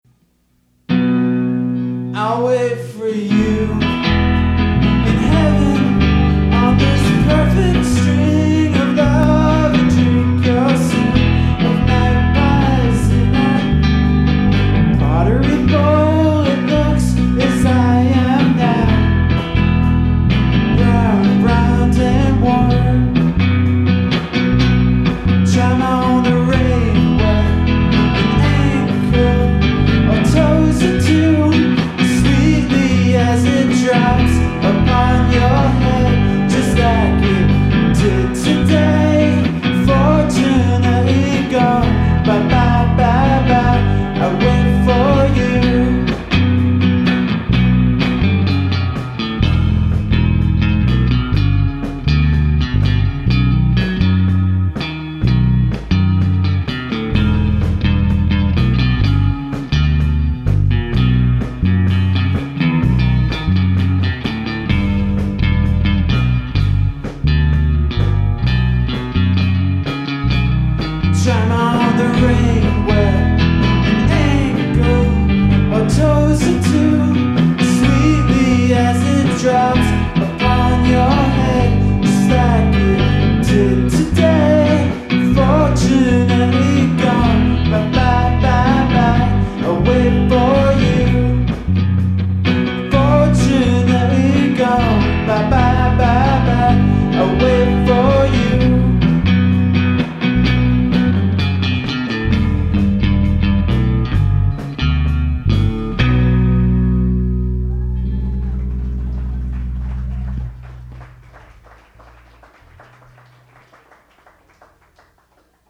cover songs
live music